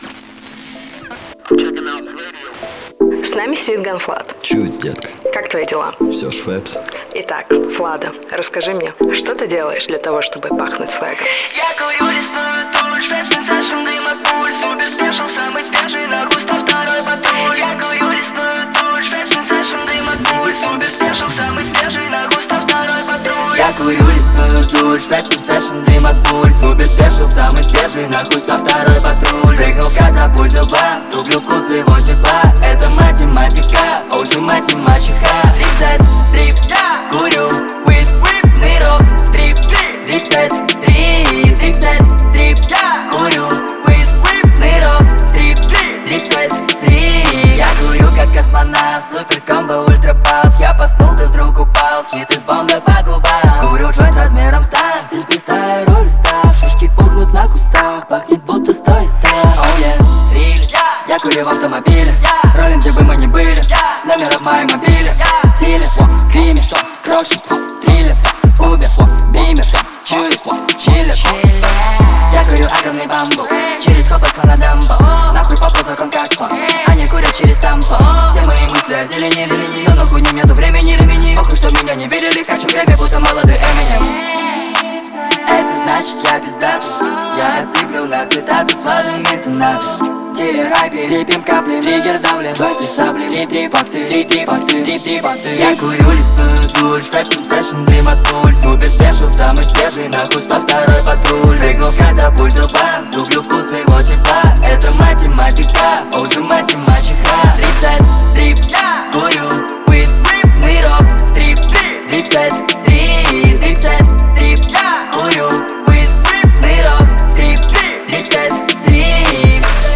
Русская поп музыка